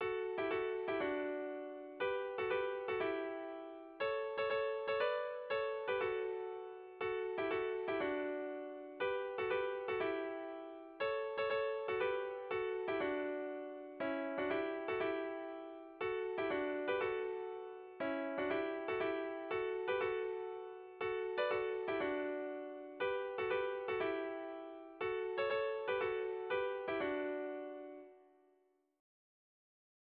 Erlijiozkoa
Zortziko handia (hg) / Lau puntuko handia (ip)
A-A-B-C